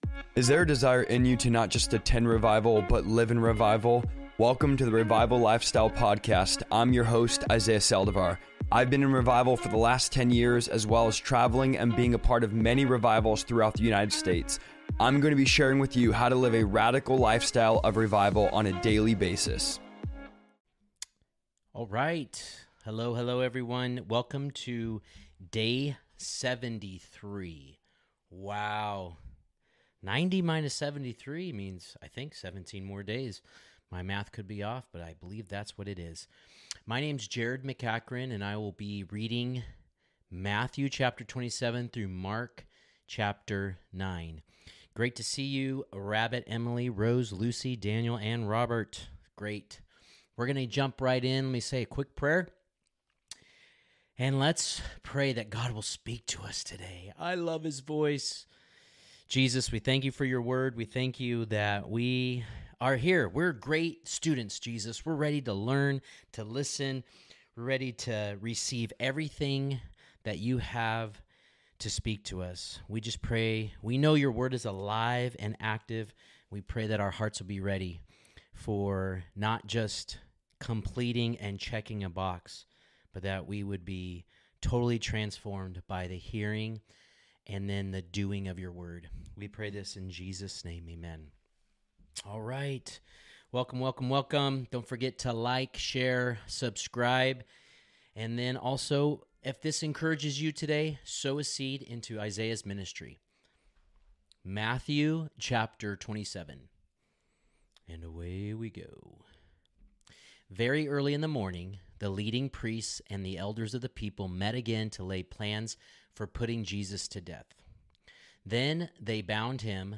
Every day, we'll dive into Scripture together in a relaxed, interactive live session where you can ask questions, share thoughts, and explore the Bible in a way that feels personal and genuine. Here’s what you can look forward to: Daily Bible Reading: I'll be reading through the Bible live, sharing my insights and reflections as we move chapter by chapter.